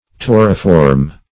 Search Result for " tauriform" : The Collaborative International Dictionary of English v.0.48: Tauriform \Tau"ri*form\, a. [L. tauriformis; taurus a bull + -form: cf. F. tauriforme.]